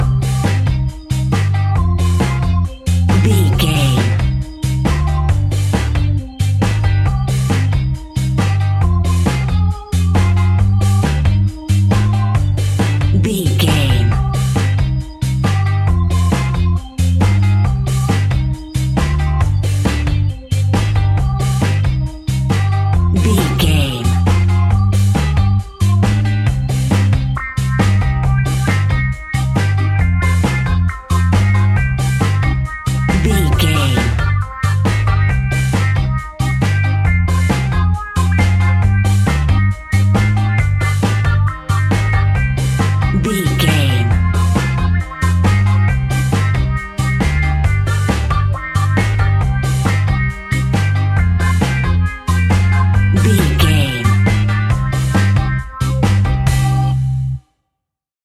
Classic reggae music with that skank bounce reggae feeling.
Aeolian/Minor
laid back
chilled
off beat
drums
skank guitar
hammond organ
percussion
horns